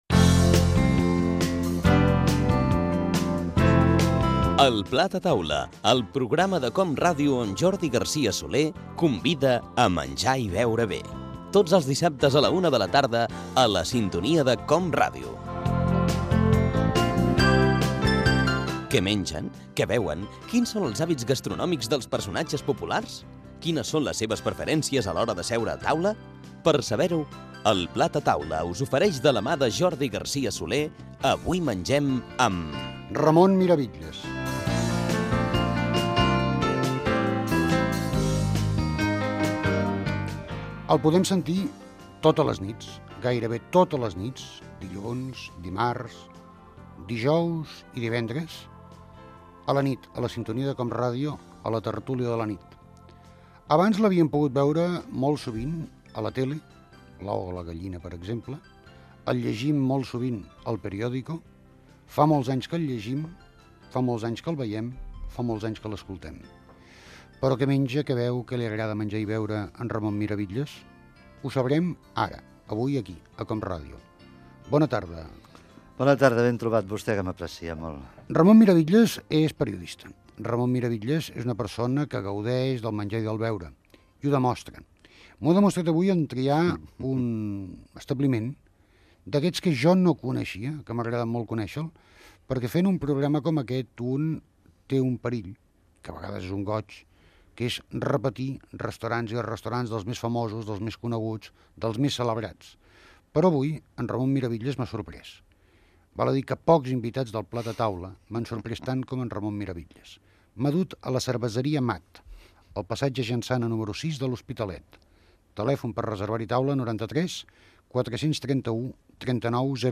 Careta del programa, presentació i fragment d'una entrevista
Entreteniment